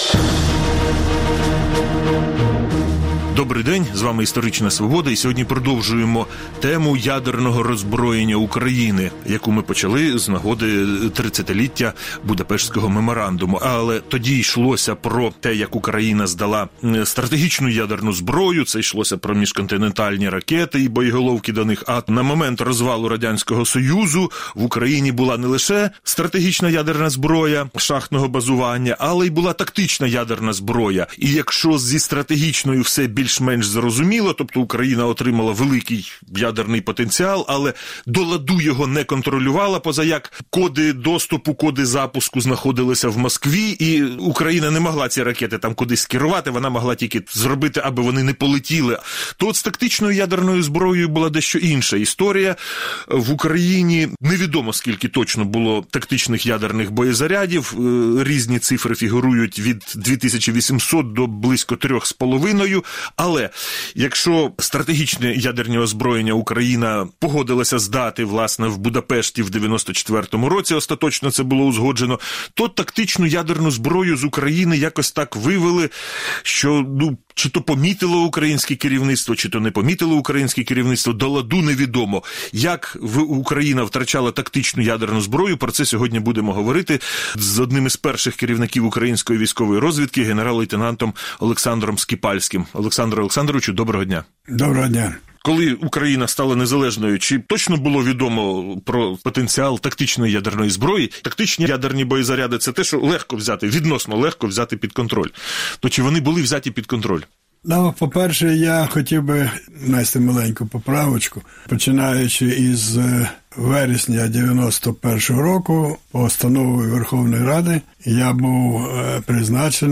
Як Україна в 1992 році віддала тактичну ядерну зброю. Розповідає генерал Скіпальський | Історична Свобода